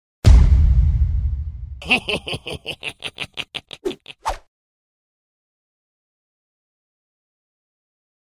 Play, download and share stopots risadinha meme original sound button!!!!
stopots-risadinha-meme.mp3